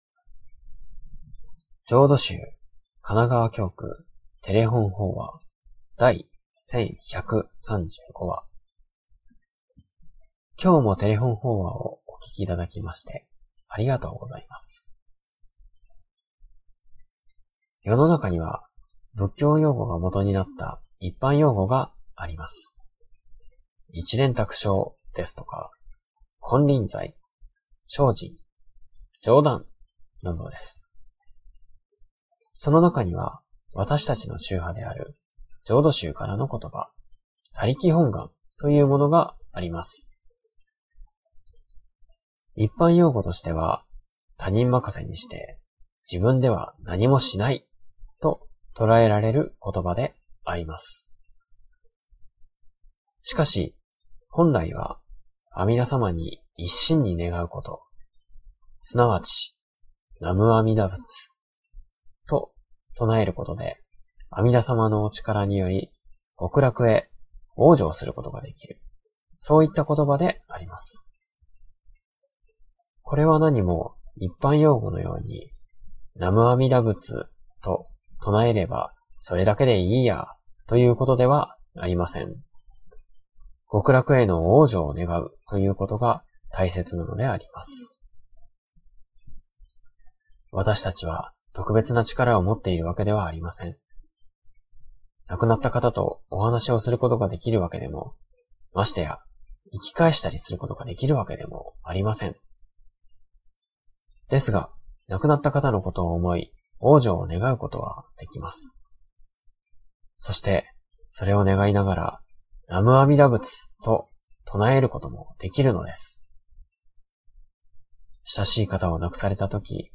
テレホン法話
法話